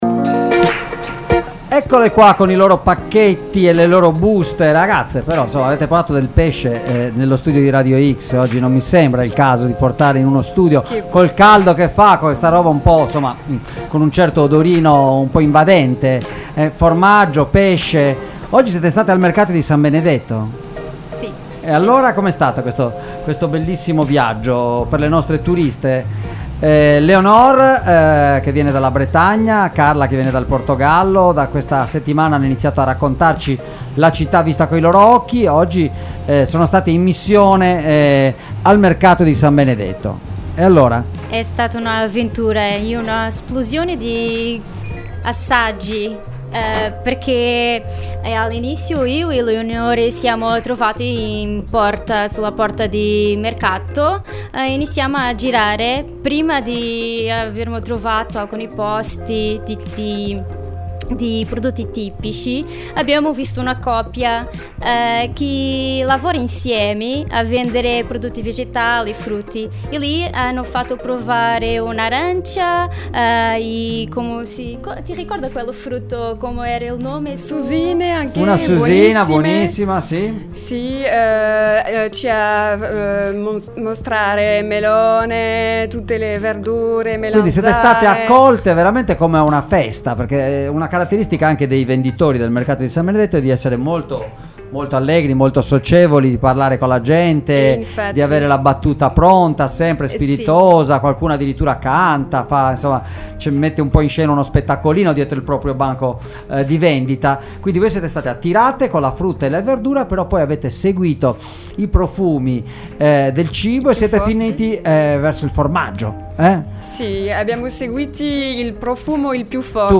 In questa puntata -tra frutta, verdura, gelati al pecorino, pesci e formaggi deliziosi-, hanno fatto un giro in uno dei luoghi più visitati e più apprezzati dai turisti: il Mercato di San Benedetto!